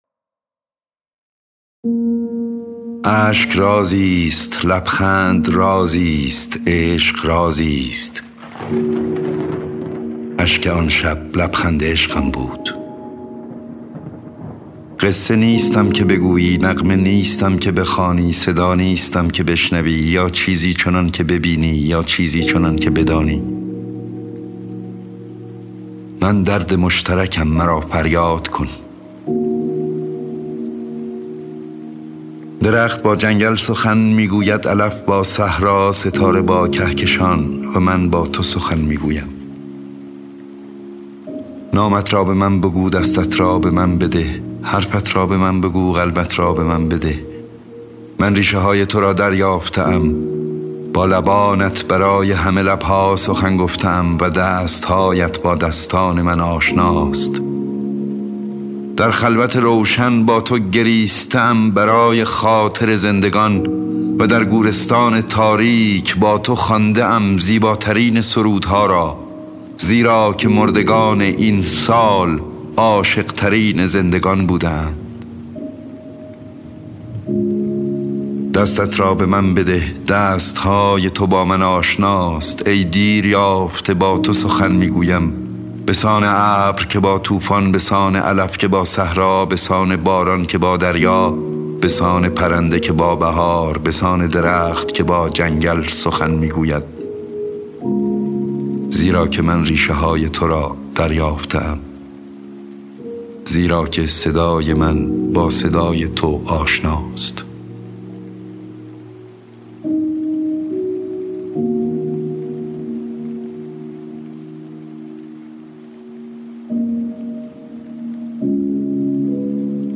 دانلود دکلمه عشق عمومی با صدای احمد شاملو
گوینده :   [احمد شاملو]